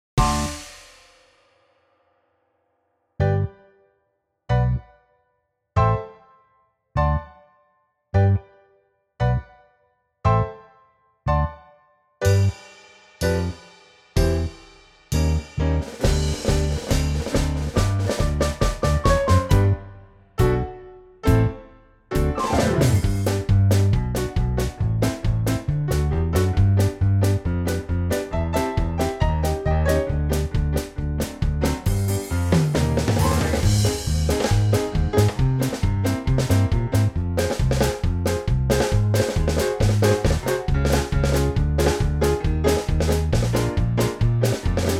This is a professional performance track